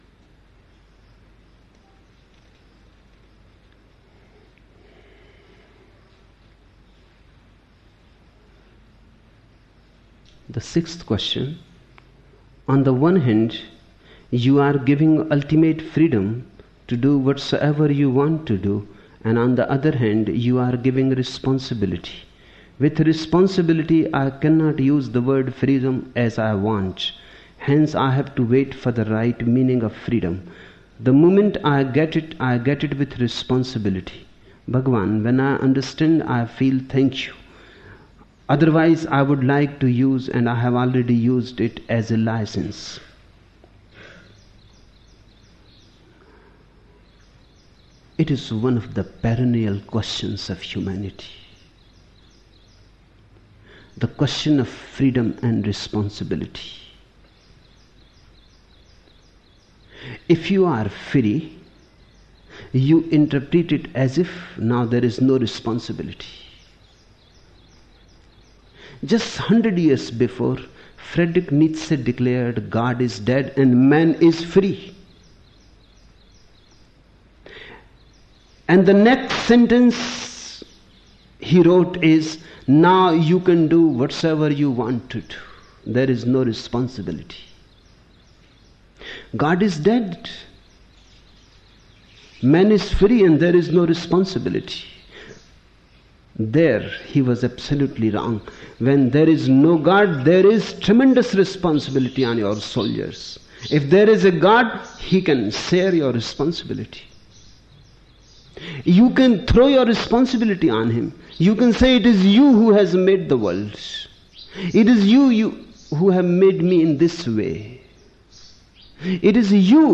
The Osho discourses in the listening meditations in this module are from discourses in which Osho gave from 1974 to 1988.
Listening Meditation: